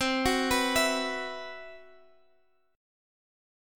CM7b5 Chord
Listen to CM7b5 strummed